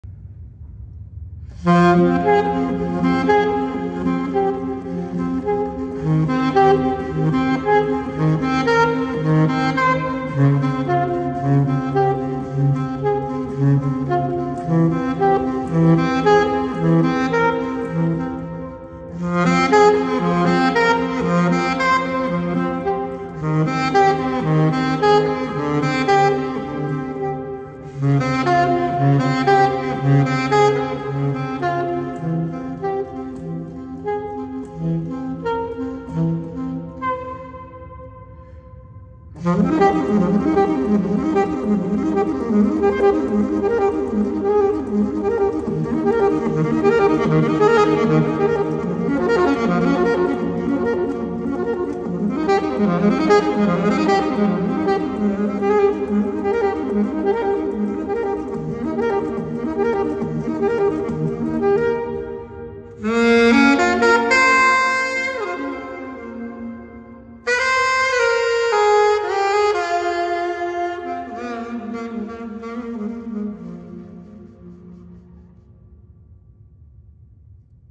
ژانر: بی کلام